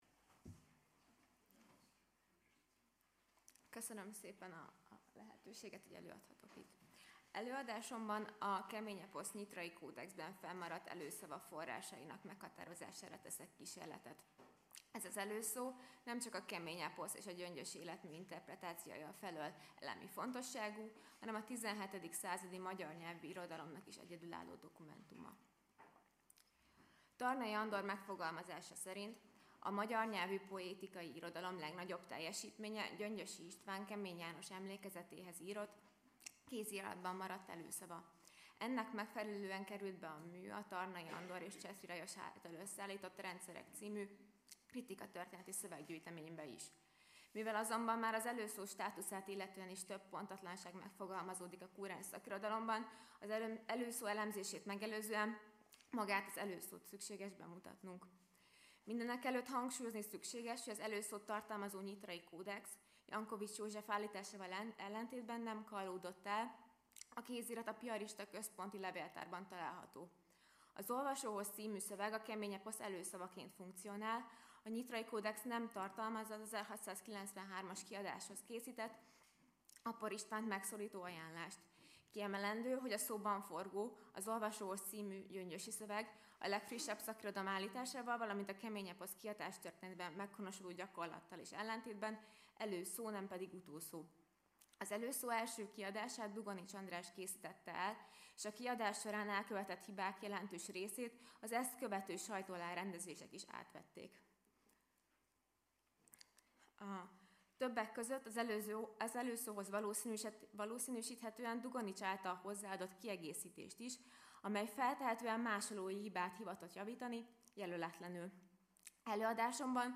Hagyományőrzés és önbecsülés. Száz éve született Tarnai Andor , Kilencedik ülés